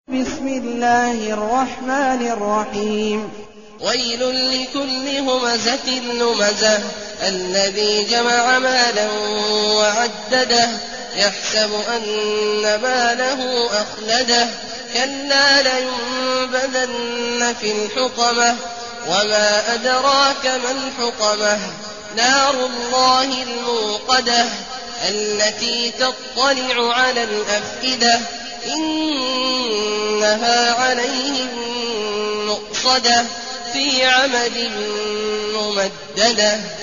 المكان: المسجد النبوي الشيخ: فضيلة الشيخ عبدالله الجهني فضيلة الشيخ عبدالله الجهني الهمزة